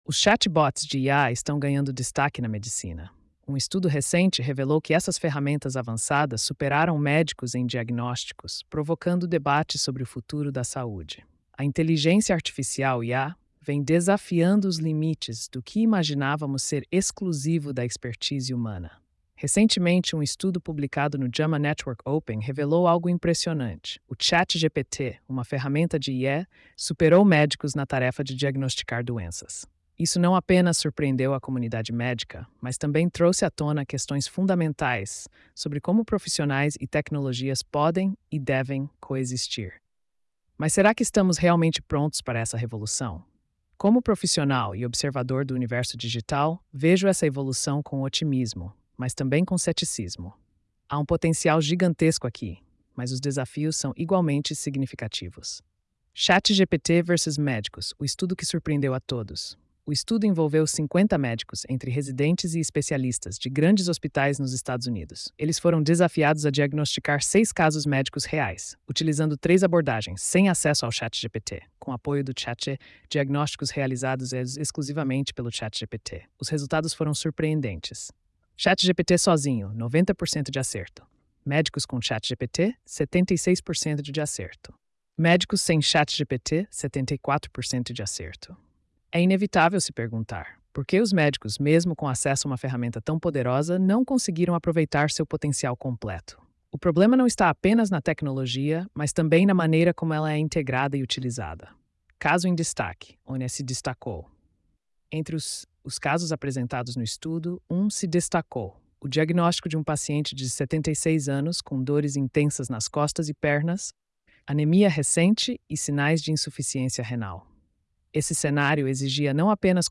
post-2585-tts.mp3